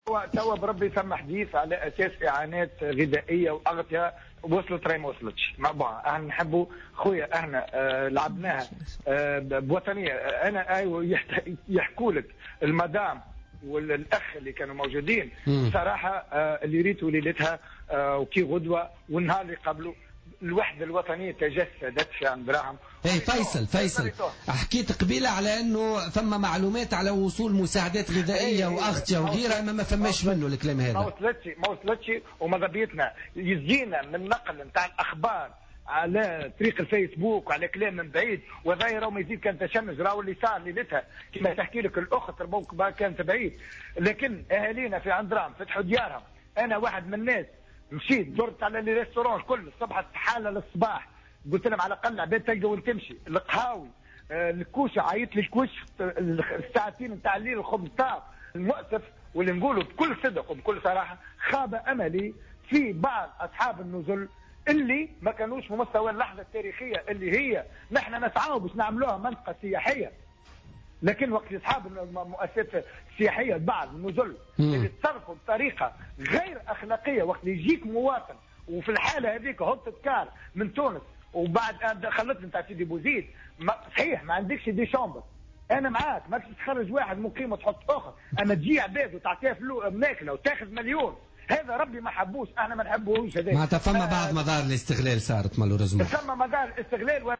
نفى النائب بمجلس الشعب، عن حزب صوت الفلاحين، فيصل التبيني في مداخلة له اليوم في برنامج "بوليتيكا" وصول أي مساعدات من مواد غذائية وأغطية إلى العائلات المتضررة من تساقط الثلوج في جندوبة، خلافا لما تم تداوله.